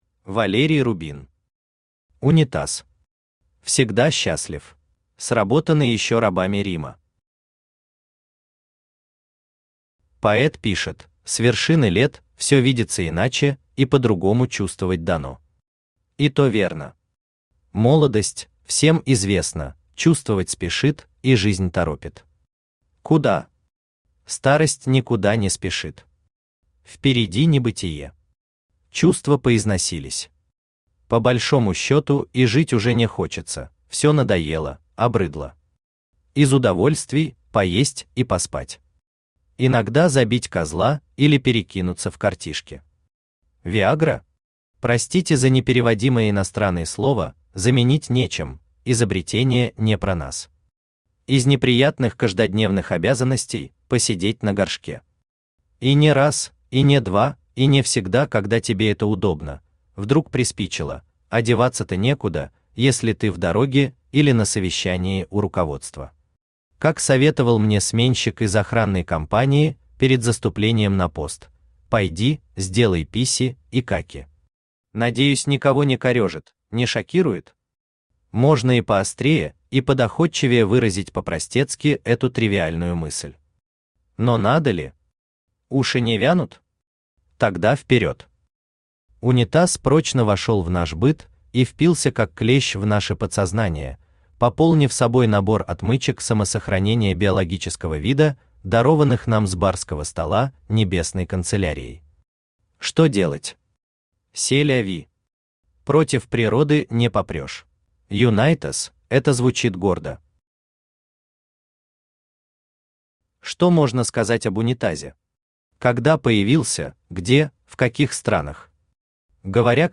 Аудиокнига Унитаз. Всегда счастлив | Библиотека аудиокниг
Всегда счастлив Автор Валерий Рубин Читает аудиокнигу Авточтец ЛитРес.